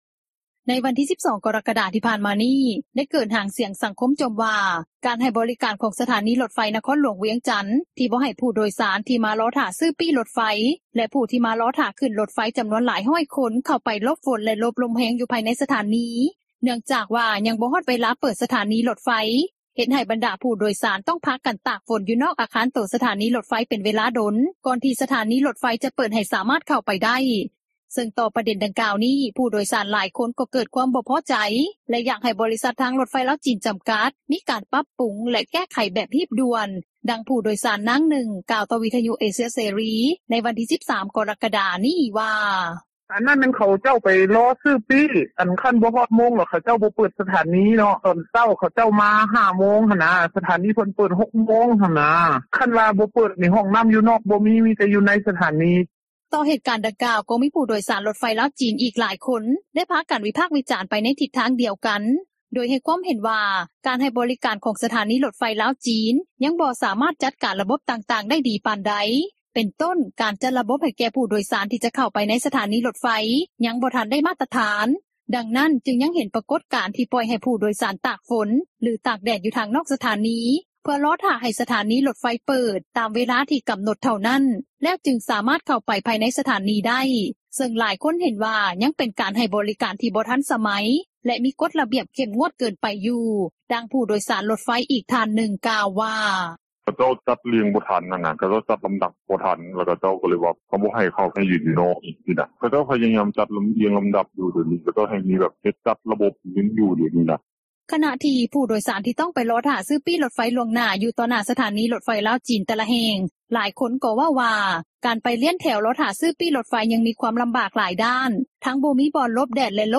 ດັ່ງຜູ້ໂດຍສານ ນາງນຶ່ງ ກ່າວຕໍ່ວິທຍຸເອເຊັຽເສຣີ ໃນວັນທີ່ 13 ກໍຣະກະດາ ນີ້ວ່າ:
ດັ່ງຜູ້ໂດຍສານຣົຖໄຟ ອີກທ່ານນຶ່ງ ກ່າວວ່າ: